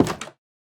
Minecraft Version Minecraft Version latest Latest Release | Latest Snapshot latest / assets / minecraft / sounds / block / cherrywood_door / toggle2.ogg Compare With Compare With Latest Release | Latest Snapshot